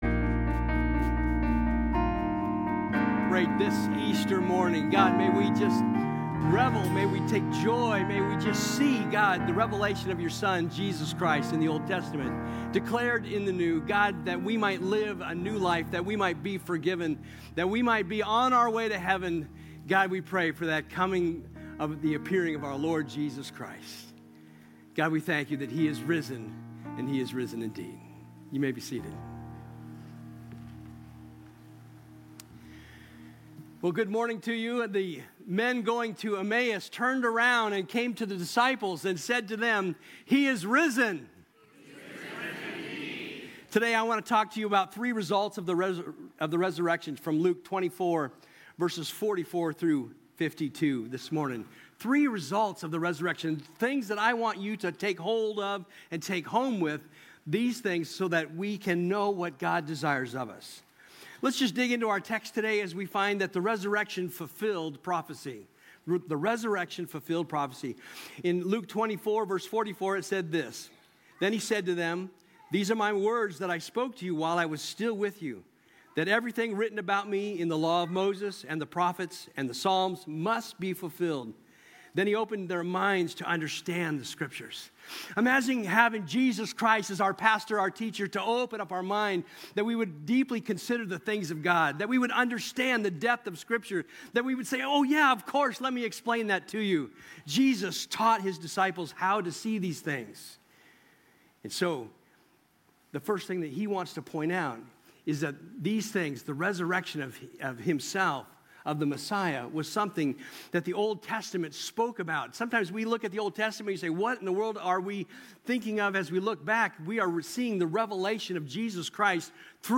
From Series: "Topical Sermons"
Join us for Easter Sunday!